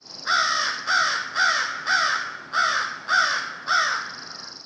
جلوه های صوتی
دانلود صدای کلاغ برای کودکان از ساعد نیوز با لینک مستقیم و کیفیت بالا
برچسب: دانلود آهنگ های افکت صوتی انسان و موجودات زنده